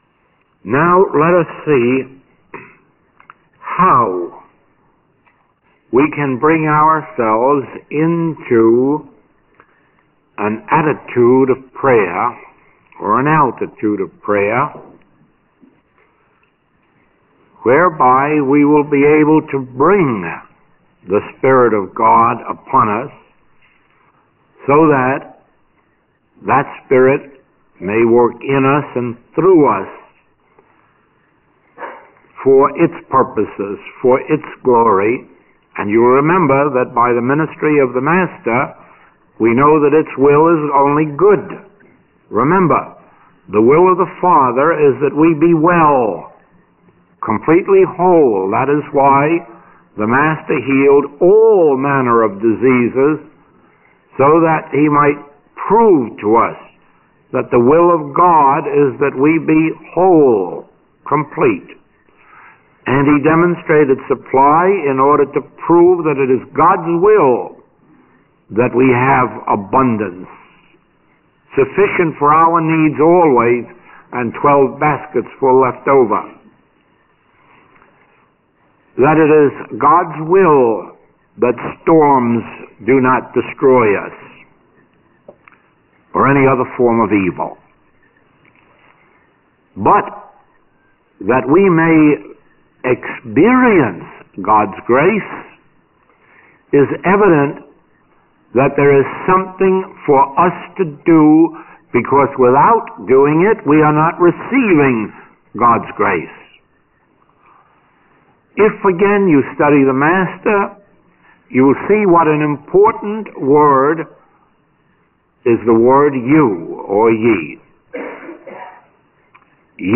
Recording 428B is from the 1961 October Los Angeles Special Class.